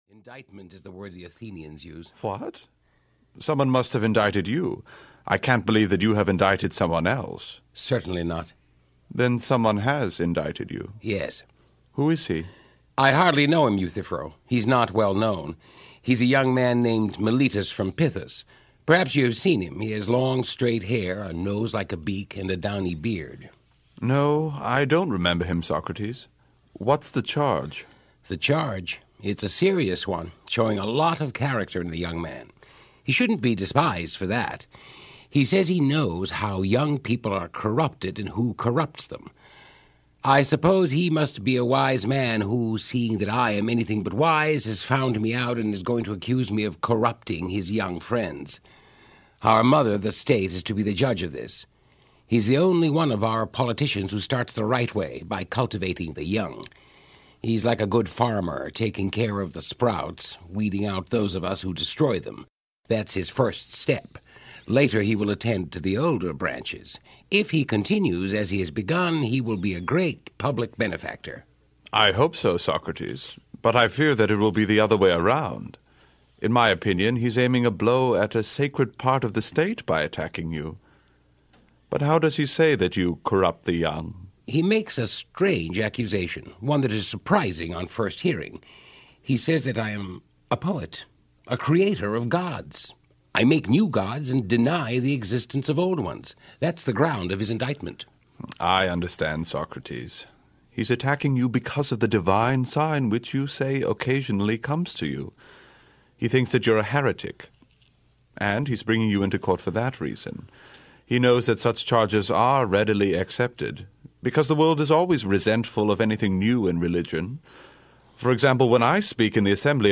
Plato’s Euthyphro (EN) audiokniha
Ukázka z knihy